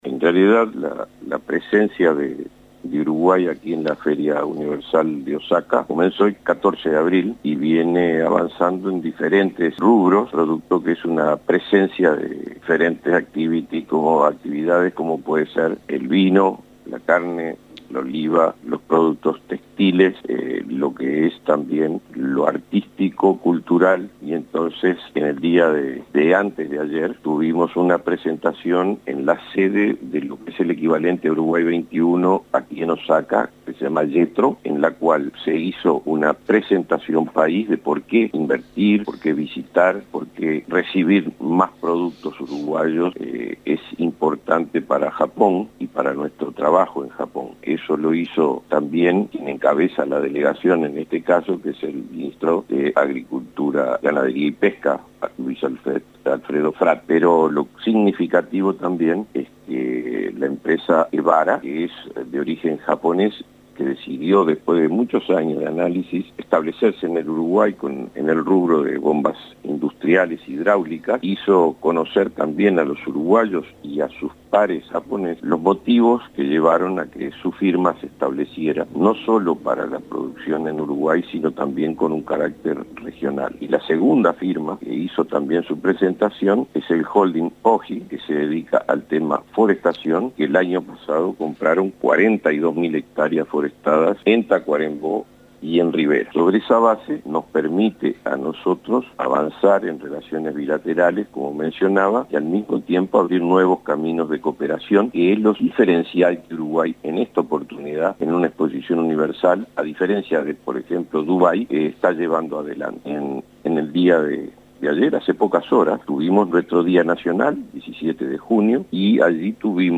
Declaraciones de Benjamín Liberoff en Expo Osaka
El comisario general de Uruguay para la Expo Osaka, Benjamín Liberoff, brindó declaraciones sobre la importancia de las relaciones bilaterales con